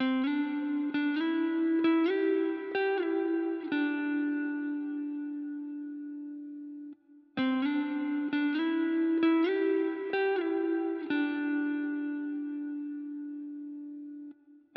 Horizon_28_130bpm_Amin.wav